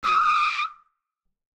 skid1.ogg